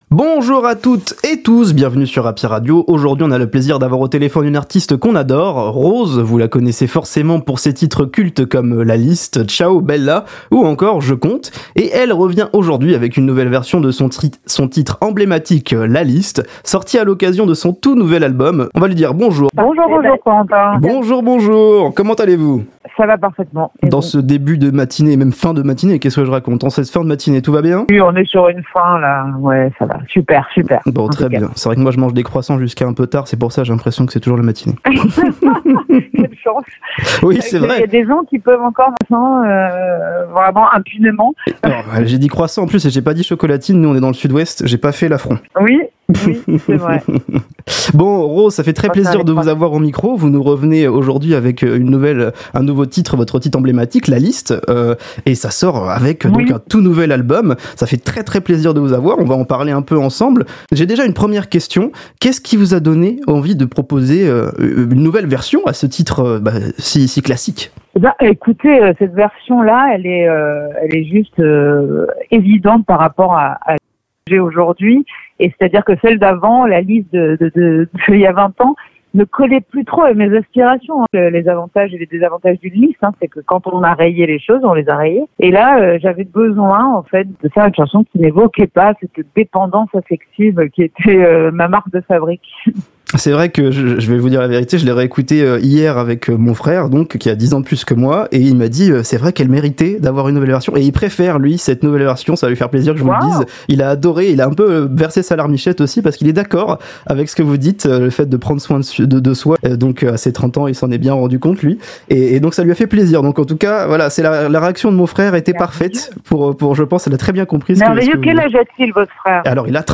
Les interviews Happy Radio Interview